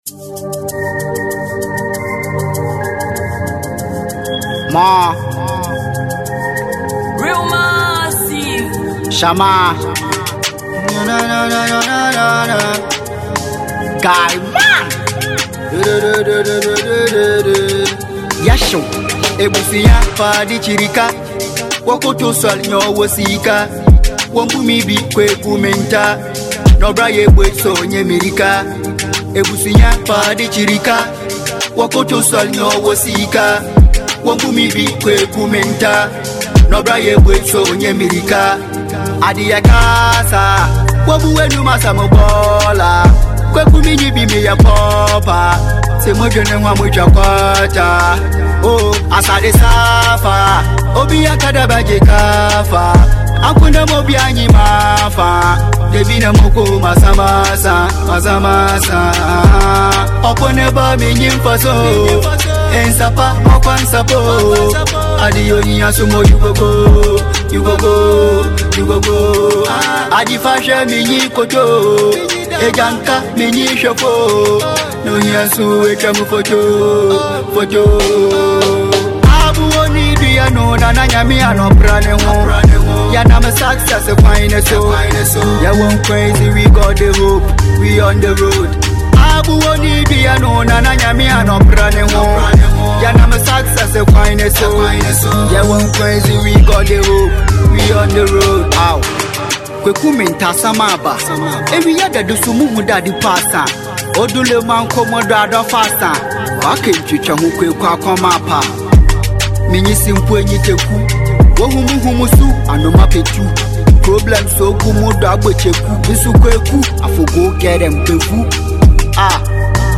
a talented Ghanaian rapper